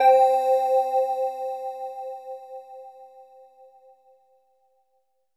LEAD C4.wav